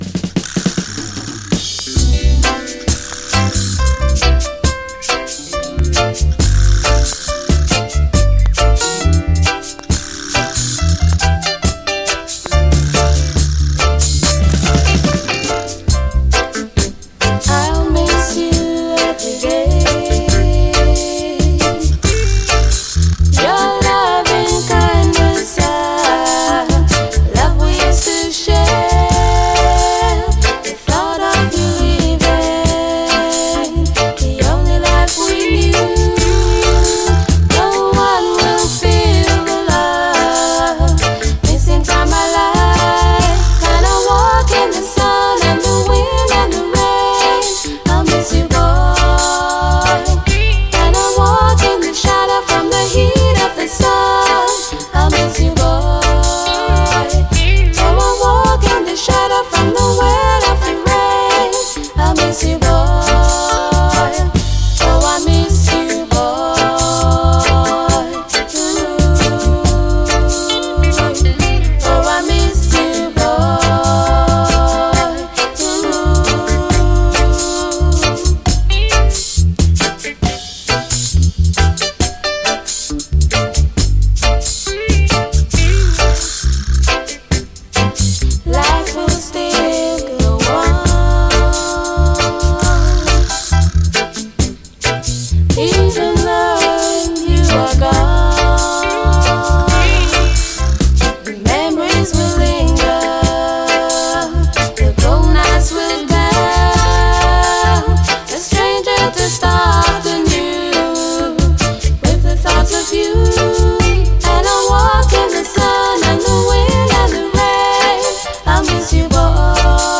REGGAE
儚いコーラス、両面グレイト！後半はダブ。